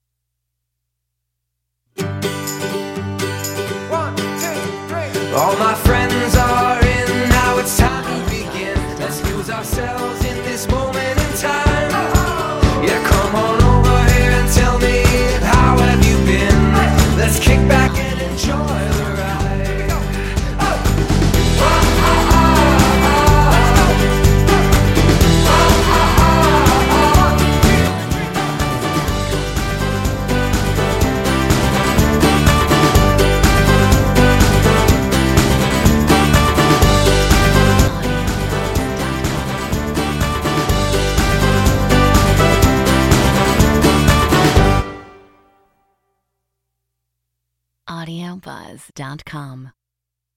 Metronome 124 BPM
Acoustic guitar Drums Percussion Tambourine Vocal samples